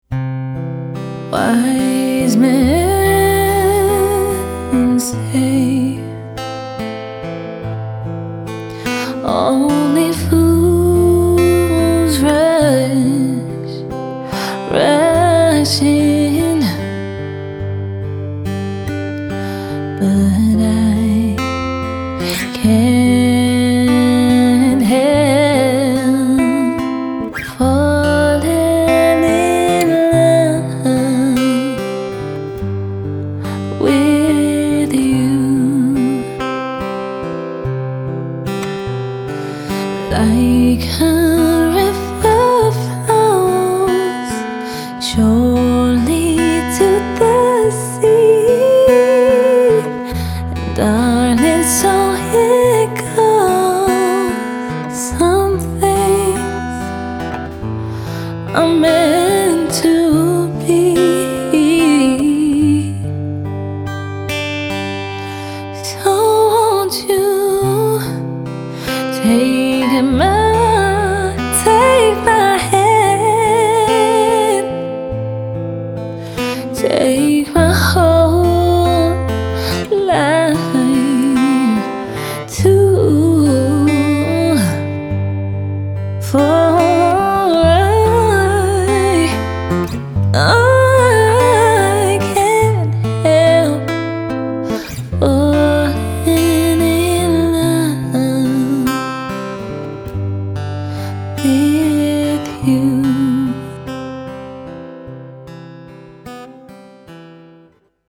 Vocals | Guitar | Looping | DJ | MC